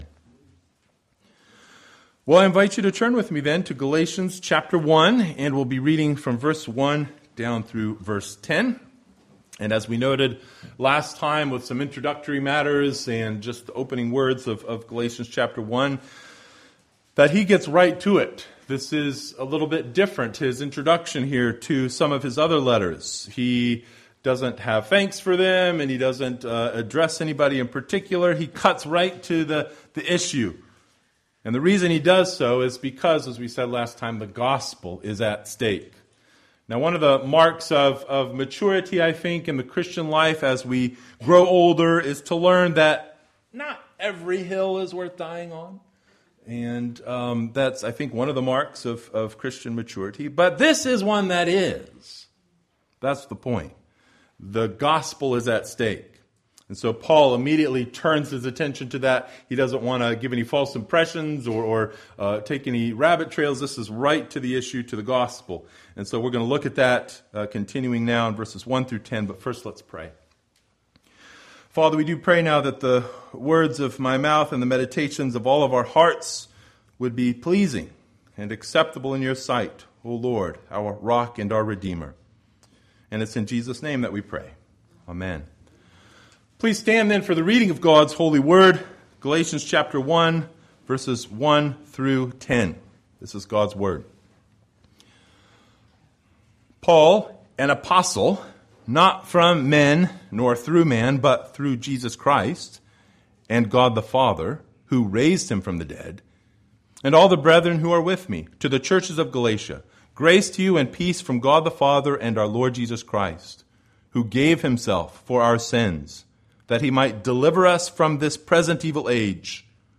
Passage: Galatians 1:1-10 Service Type: Sunday Morning Bible Text